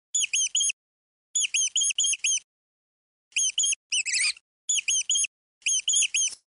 Звуки мышей
Писк мышей второй вариант